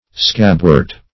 scabwort - definition of scabwort - synonyms, pronunciation, spelling from Free Dictionary Search Result for " scabwort" : The Collaborative International Dictionary of English v.0.48: Scabwort \Scab"wort`\, n. (Bot.) Elecampane.